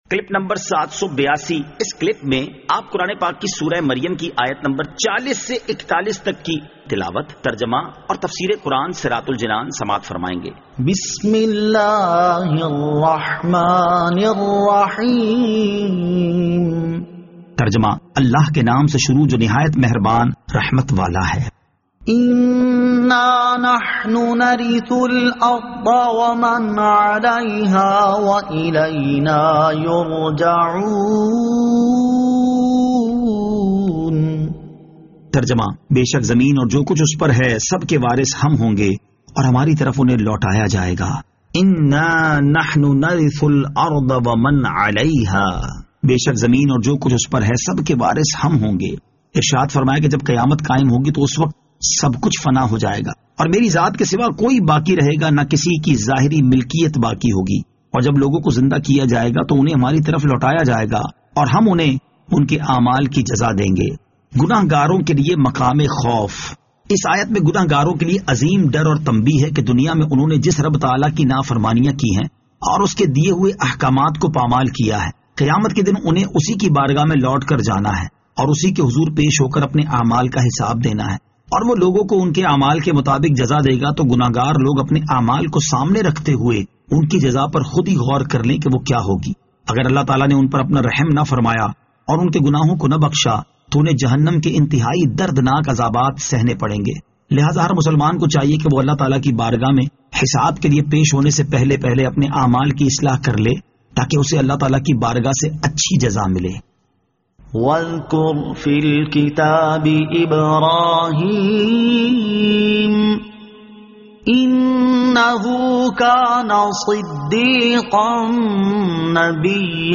Surah Maryam Ayat 40 To 41 Tilawat , Tarjama , Tafseer